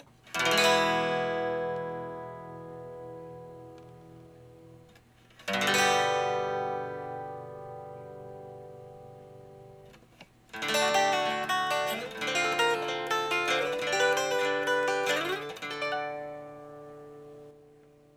All recordings in this section were recorded with an Olympus LS-10. The amp is an Axe-FX Ultra set as outlined below. The speaker is a QSC K12. All guitar knobs are on 10 for all recordings.
1986 X-500 Acoustic
I recorded them as I would an acoustic guitar but sitting with the guitar on my lap and my Olympus LS-10 sitting on the table in front of me point directly at the neck pickup of the guitar.